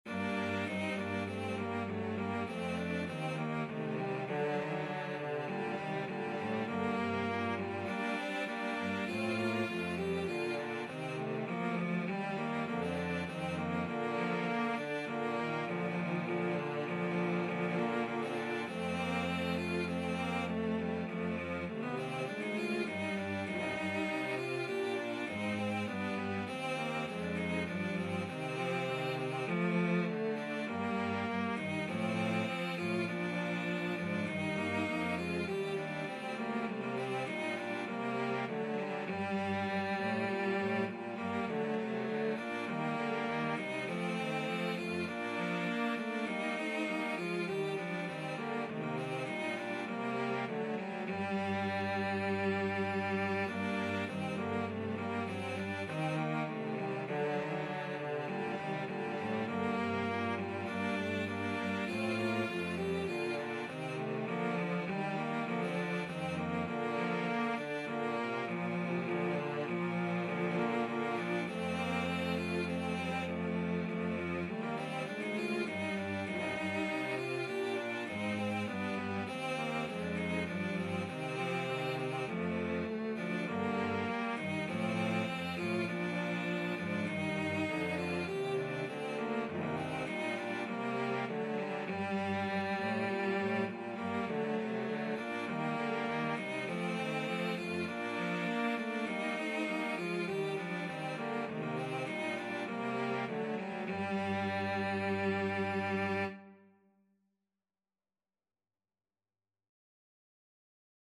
4/4 (View more 4/4 Music)
Cello Trio  (View more Intermediate Cello Trio Music)
Classical (View more Classical Cello Trio Music)